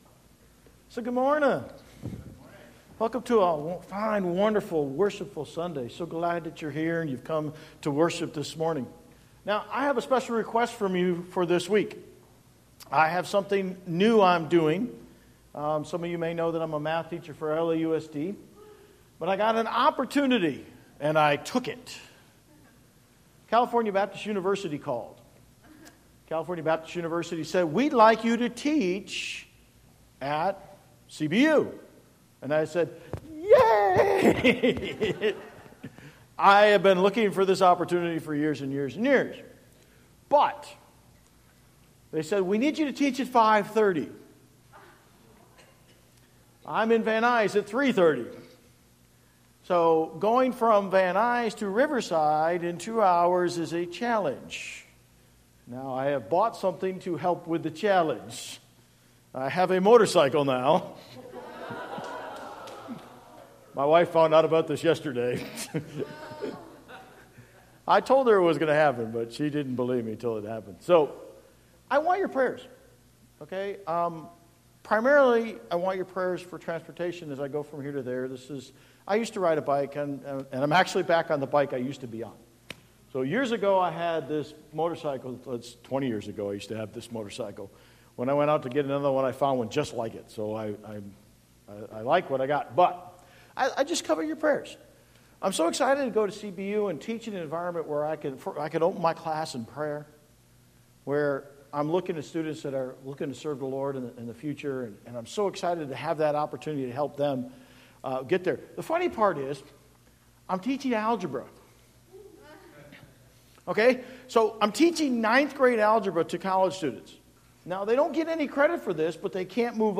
Bible Text: Romans 10:9-10 | Preacher